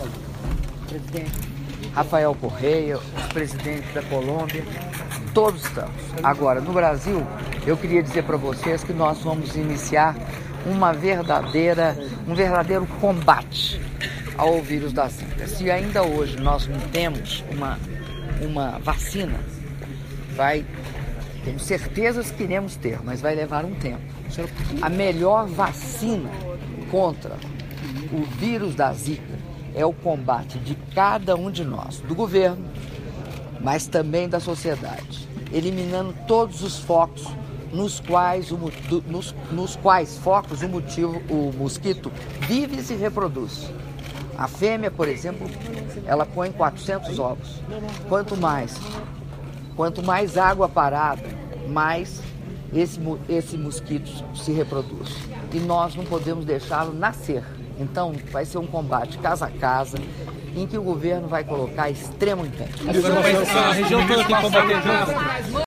Áudio da entrevista coletiva concedida pela Presidenta da República, Dilma Rousseff, após declaração à imprensa - Quito/Equador (01min05s)